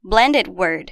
Confira a pronúncia em inglês desses dois termos:
blended-word.mp3